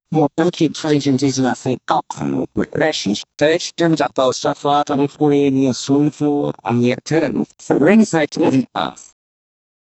Can you make a loop able engine idle sound of the Perkins-404D-22 diesel 4-cylinder for a videogame. Make it from the outside and roughly 10 seconds in length